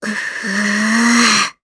Gremory-Vox_Casting2_jp.wav